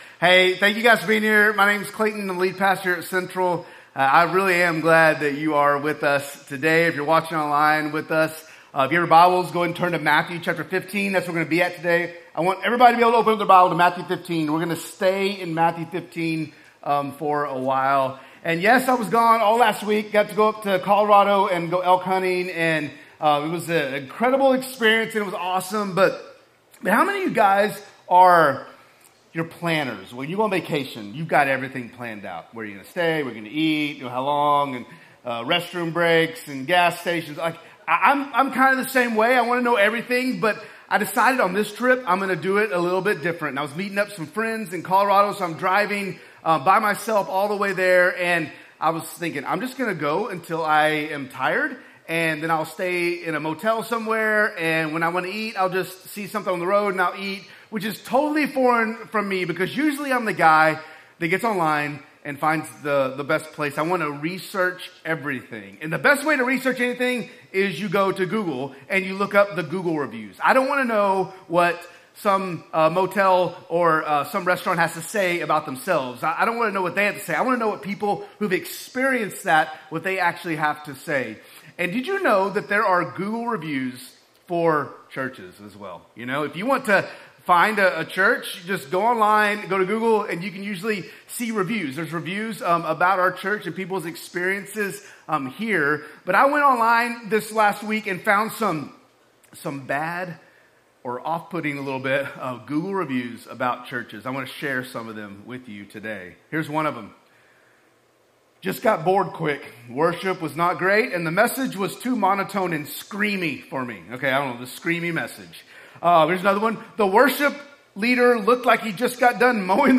A message from the series "Crossing the Line."